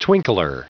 Prononciation du mot twinkler en anglais (fichier audio)
Prononciation du mot : twinkler